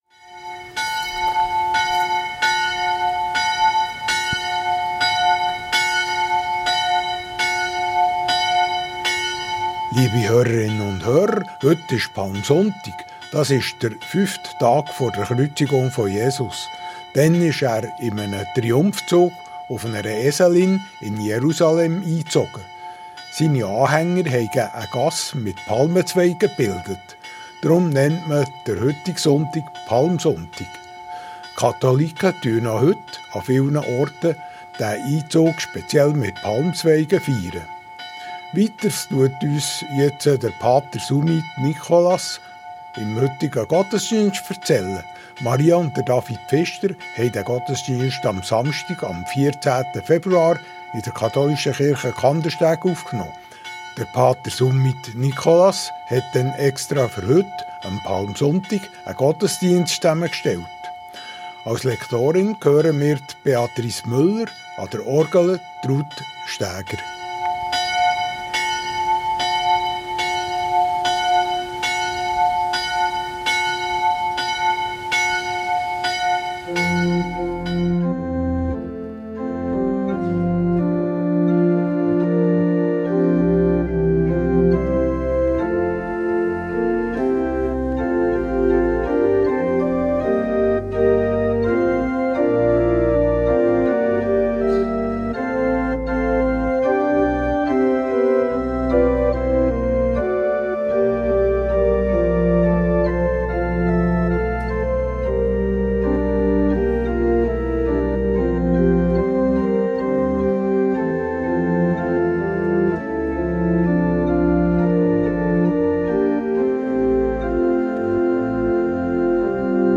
Marienkirche Kandersteg der katholischen Pfarrei Frutigen ~ Gottesdienst auf Radio BeO Podcast
BeO Gottesdienst